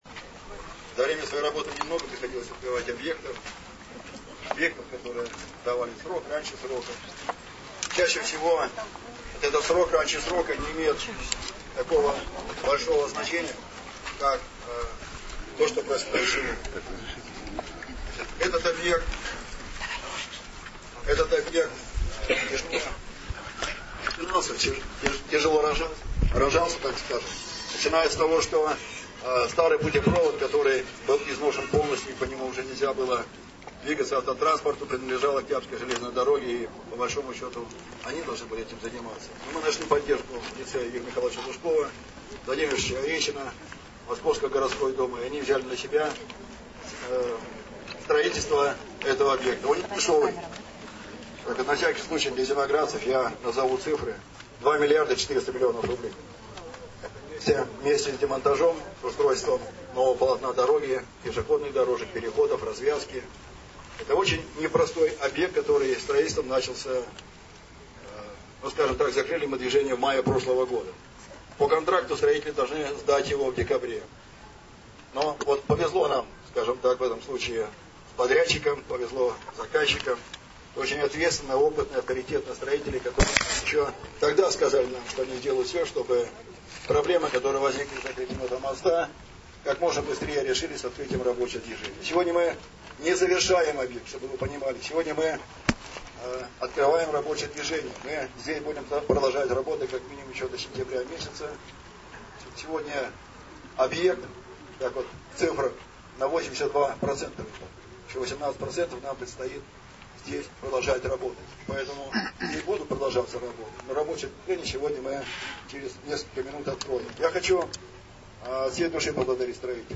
Послушать выступление и интервью префекта Анатолия Смирнова (7:23)